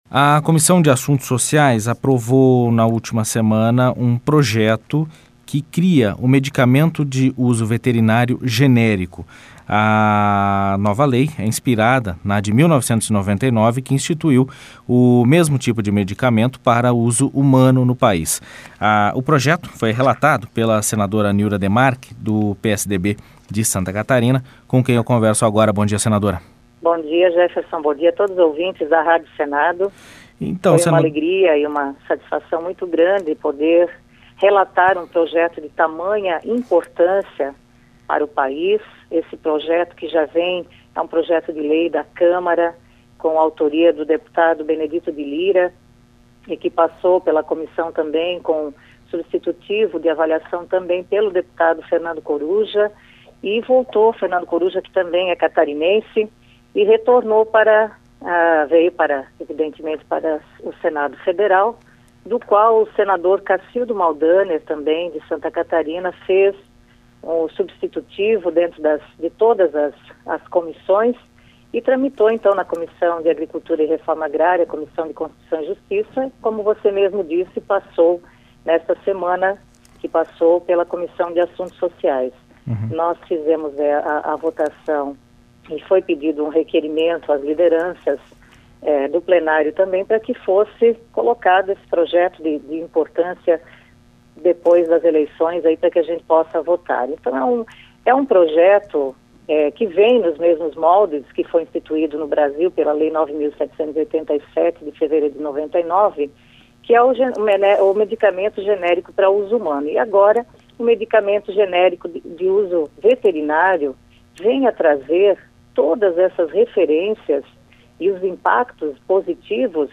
Programa diário com reportagens, entrevistas e prestação de serviços